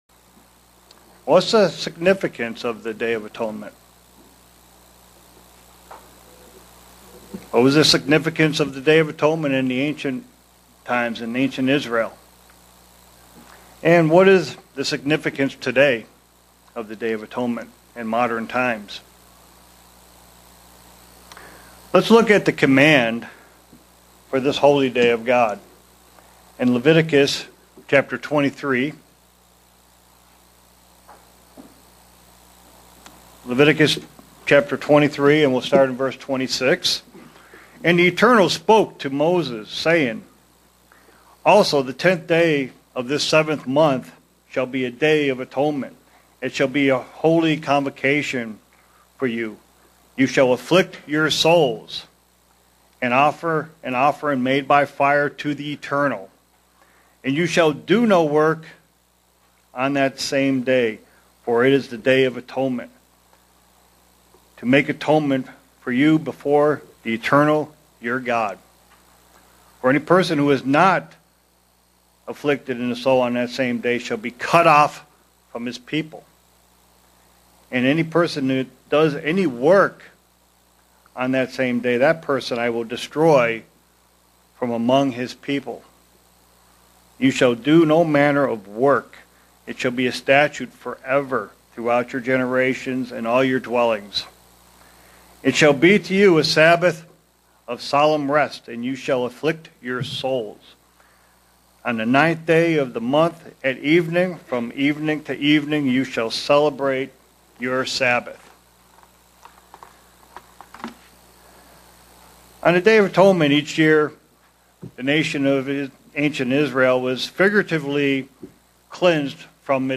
Print It shows how we are not self-sufficient and how fragile we are. sermon Studying the bible?